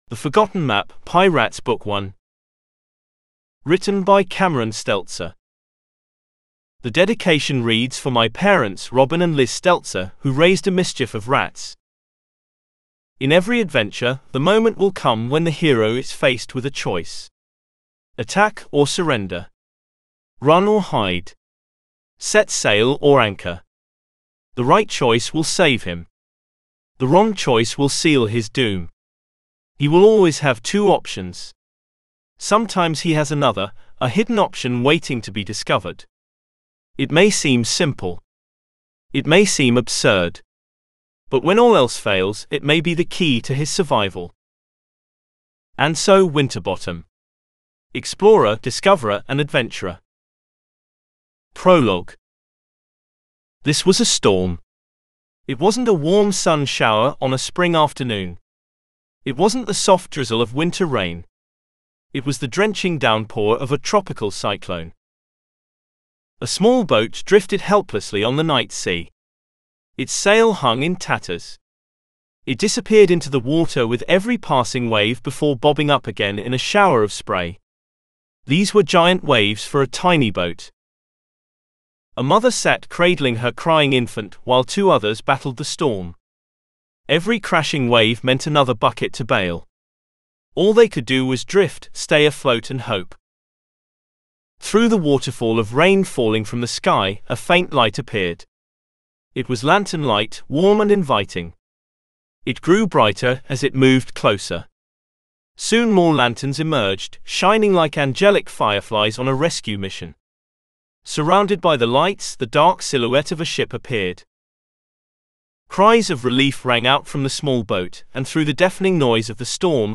The Forgotten Map Audio Excerpt mp3 (Artificial Intelligence reading)
The Forgotten Map Excerpt AI Audio.mp3